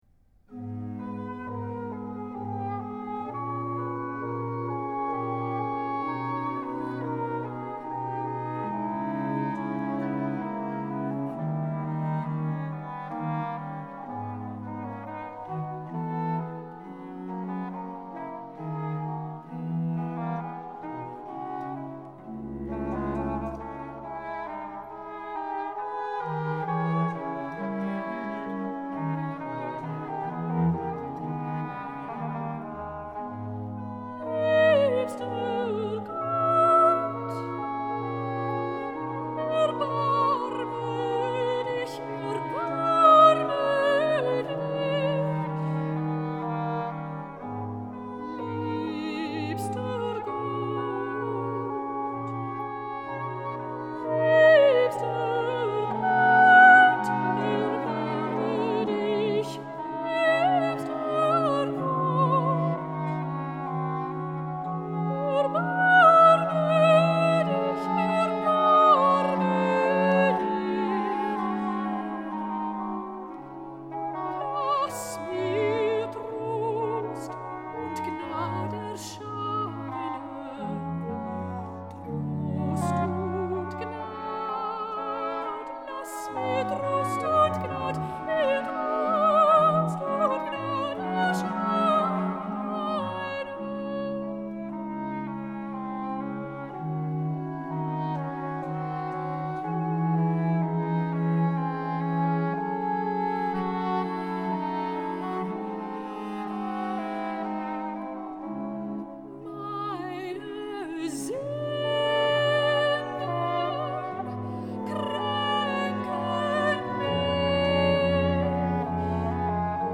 5. Air de soprano
Hautbois da caccia I/II, Continuo
05-5.-Air-de-soprano-_-Liebster-Gott-Erbarme-Dich.mp3